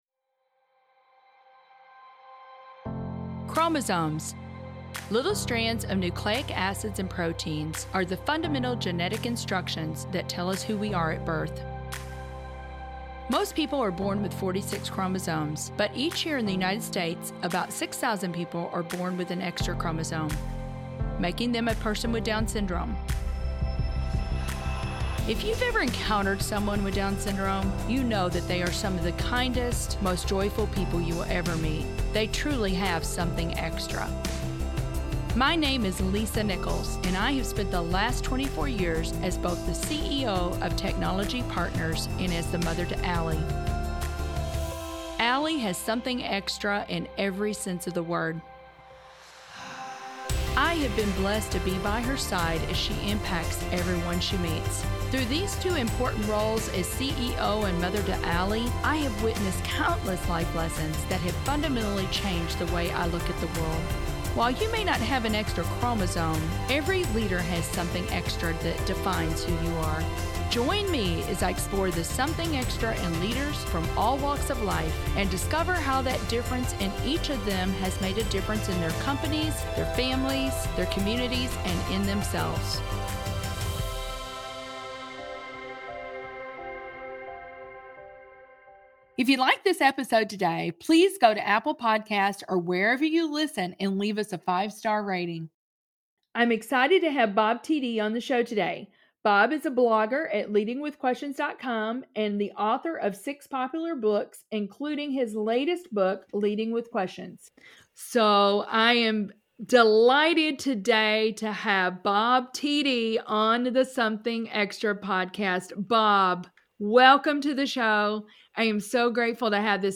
While all of our guests have wonderful insights into Something Extra, this might be one of our favorites.